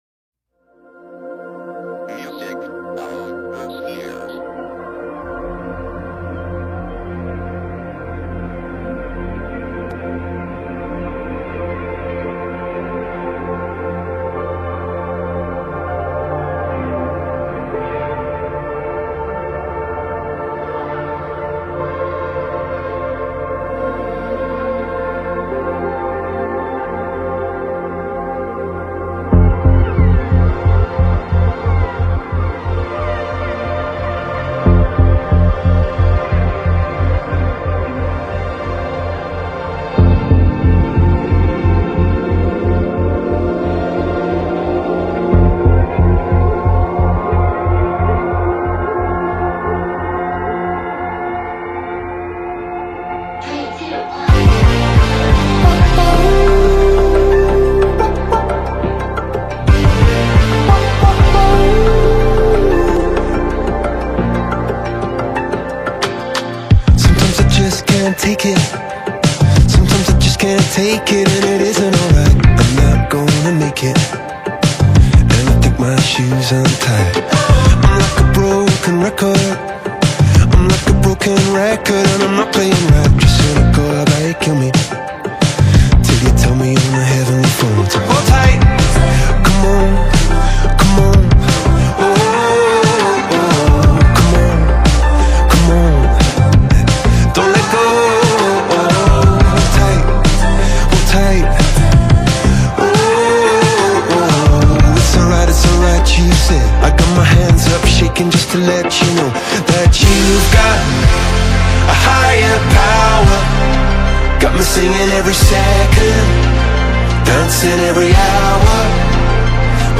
پاپ راک